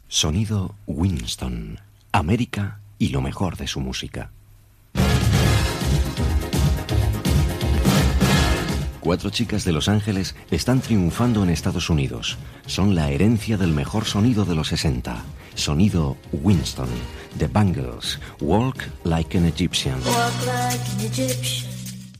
Publicitat i presentació d'un tema musical
Musical